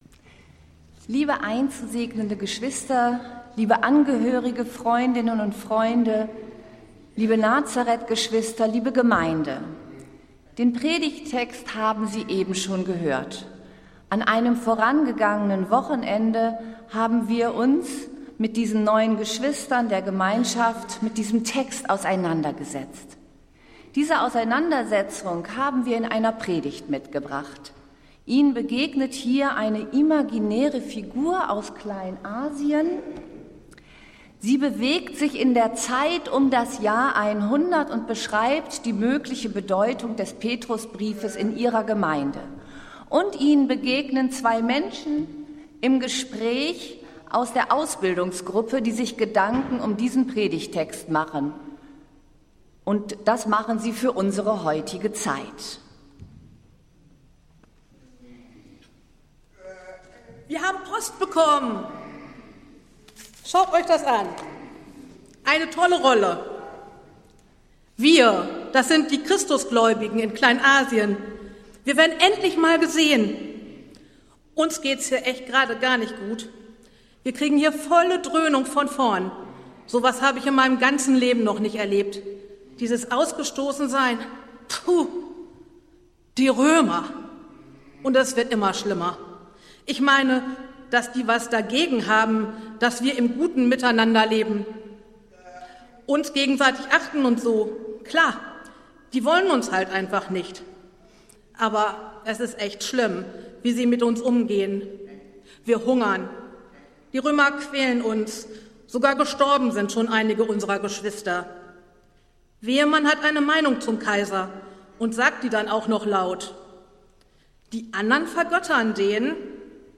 Predigt des Gottesdienstes aus der Zionskirche vom Sonntag, 28. September 2025
Wir haben uns daher in Absprache mit der Zionskirche entschlossen, die Predigten zum Nachhören anzubieten.